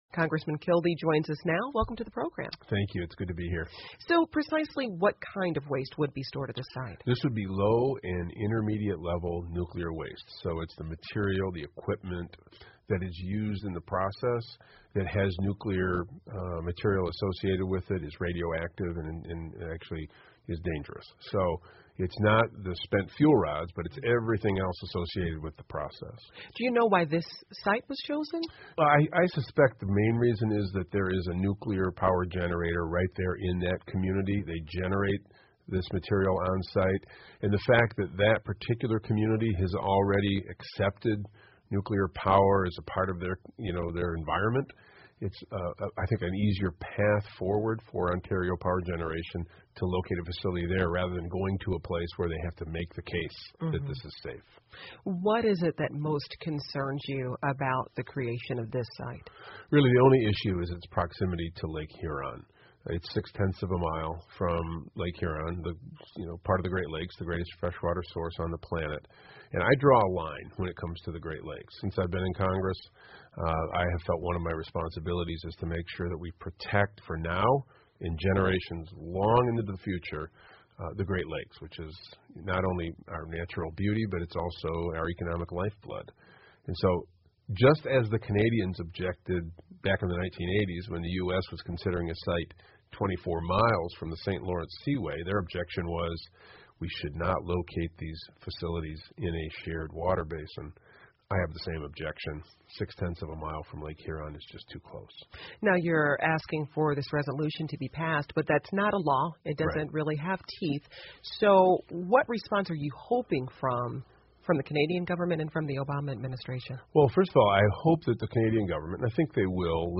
密歇根新闻广播 美议员呼吁停止在休伦湖建核废料站 听力文件下载—在线英语听力室